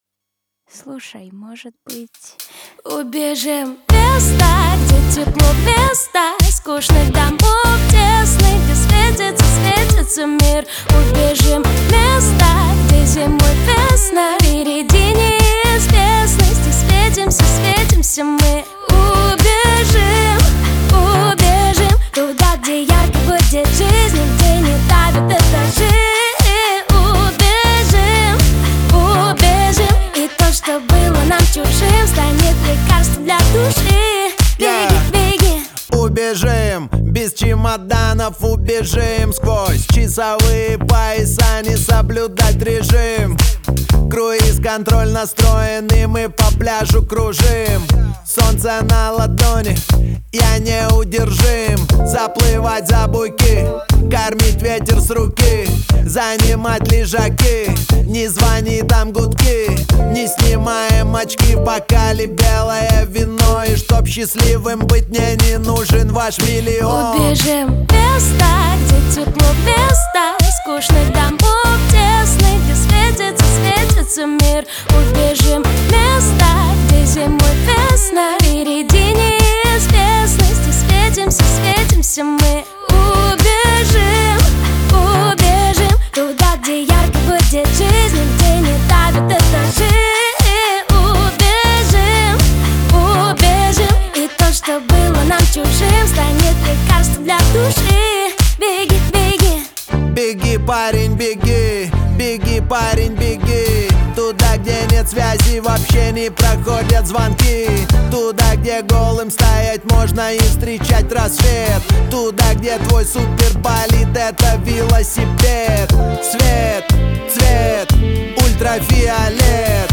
Жанр: Узбекские треки